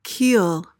PRONUNCIATION: (keel) MEANING: noun:1.